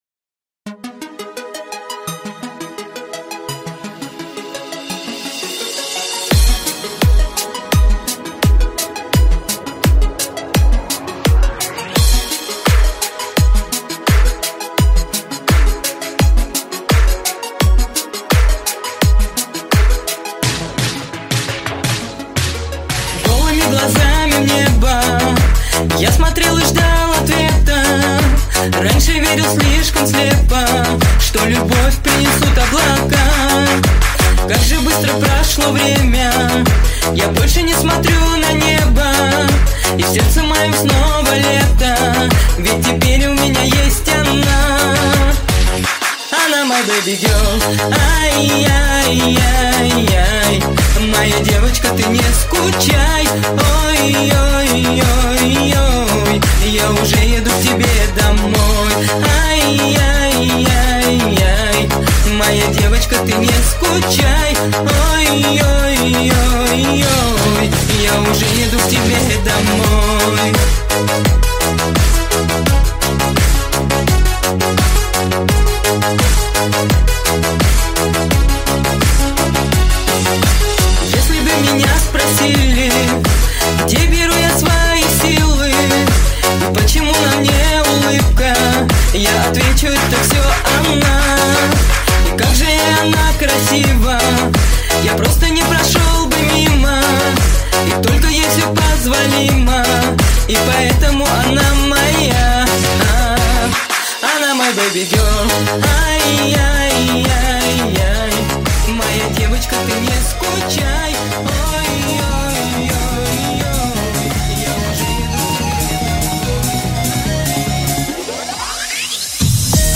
Euro Dance Fractal Mix
Euro-Dance-Fractal-Mix.mp3